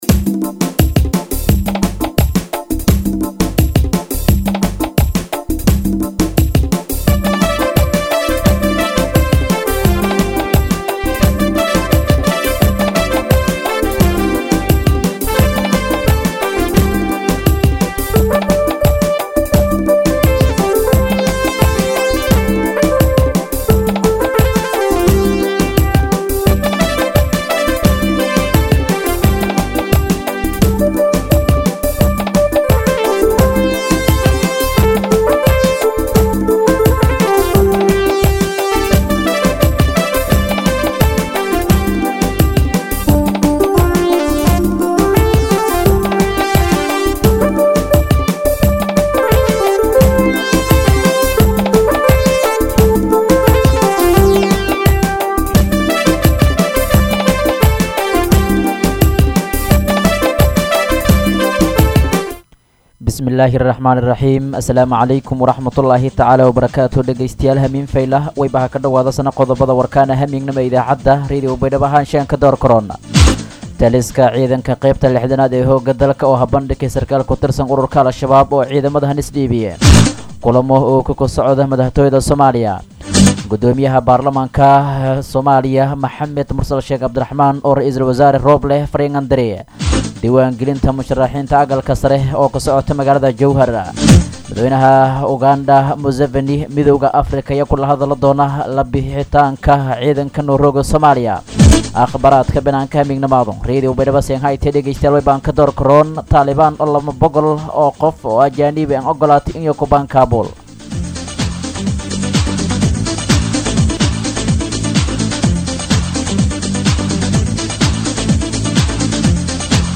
DHAGEYSO:-Warka Habenimo Radio Baidoa 9-9-2021